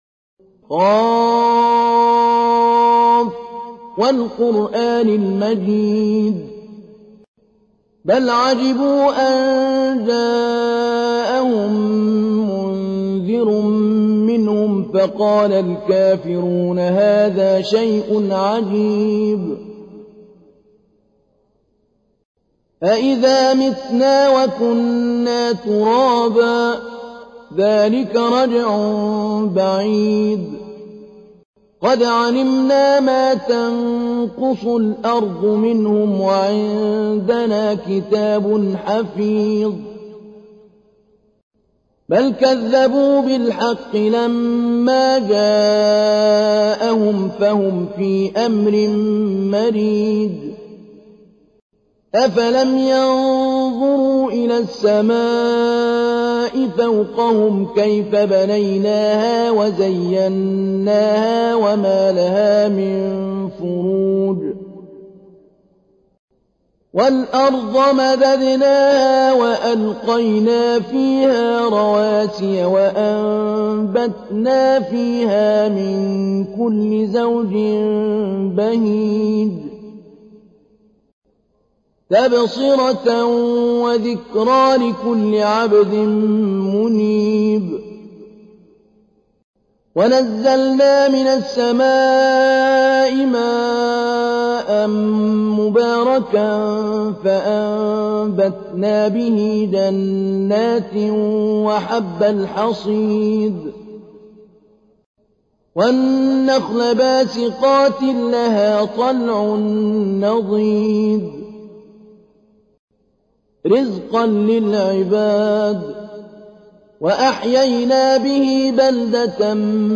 تحميل : 50. سورة ق / القارئ محمود علي البنا / القرآن الكريم / موقع يا حسين